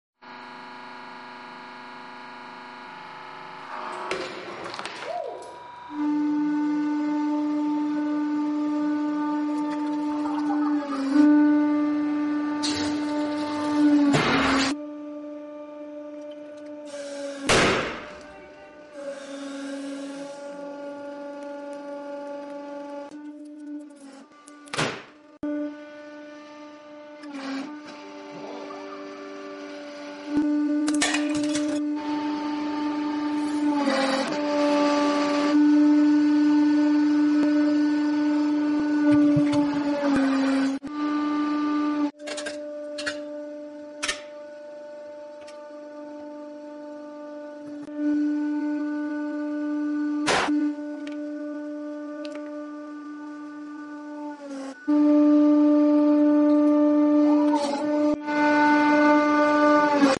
Best Hydraulic Press, See More Sound Effects Free Download